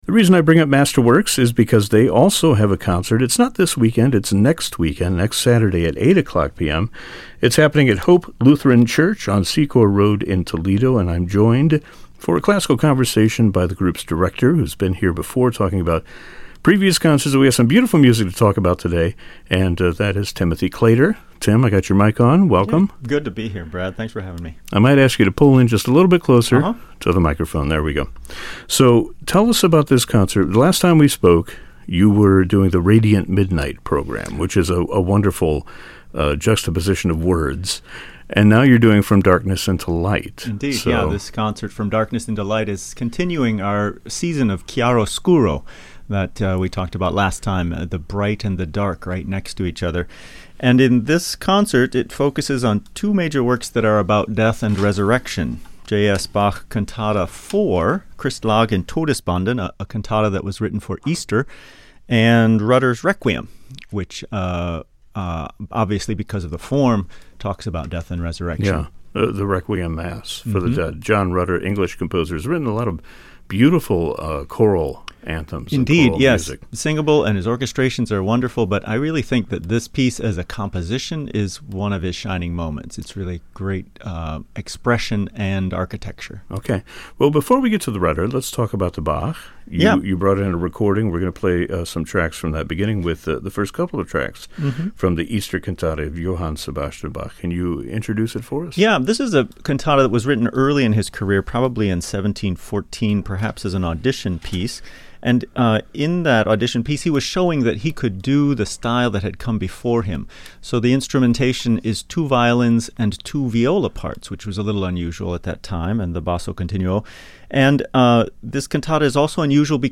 (Note: due to copyright issues, music selections have been edited out of this podcast).